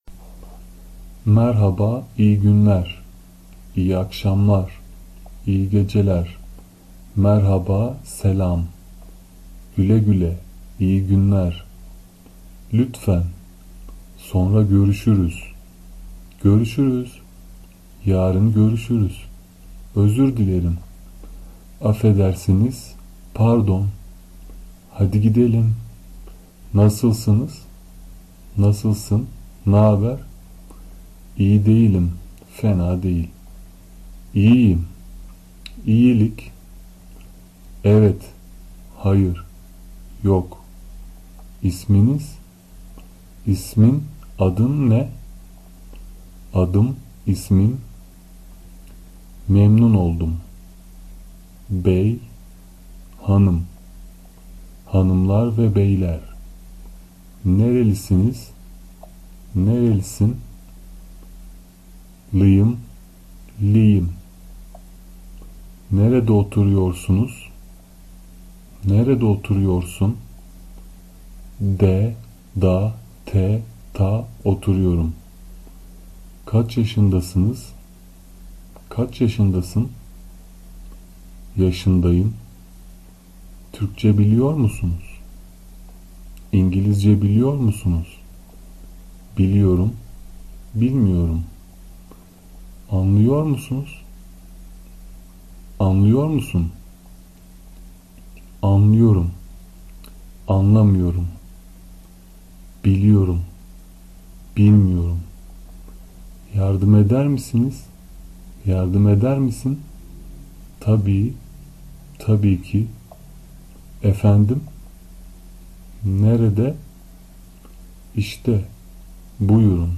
فایل صوتی تلفظ این عبارات و کلمات مهم زبان ترکی استانبولی نیز در این بخش برای شما عزیزان قرار داده شده است.
تلفظ ( با فونتیک )
سلام Merhaba! MARE-HA-BA see above
خدانگهدارHoşçakal! HOSH-CHA-KAL